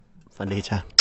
หมวดหมู่: เสียงมีมไทย